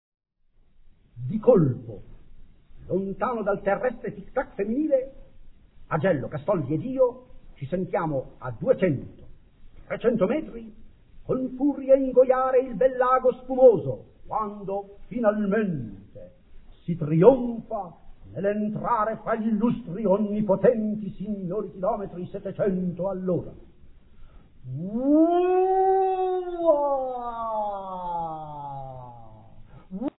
Archivi Sonori del Futurismo - Vol. 1 - Le Voci Storiche
Filippo Tommaso Marinetti declama: